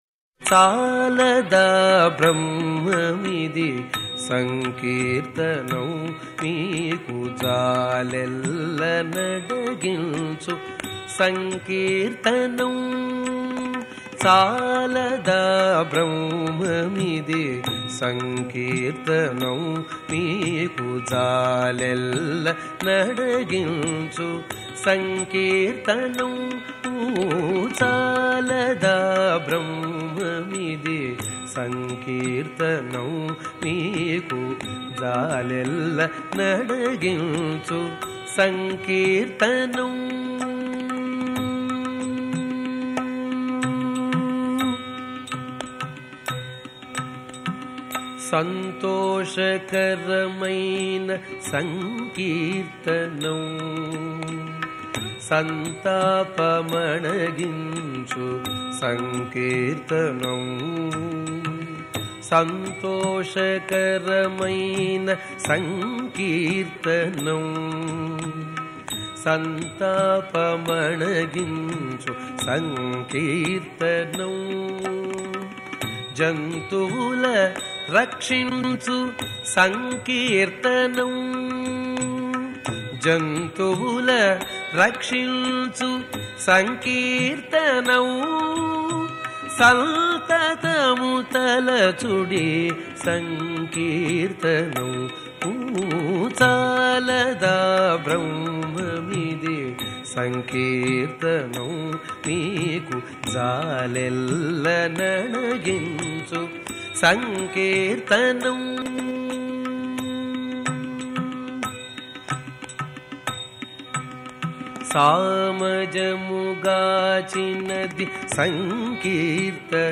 సంకీర్తన
పాడినవారు సంగీతం గరిమెళ్ళ బాలకృష్ణప్రసాద్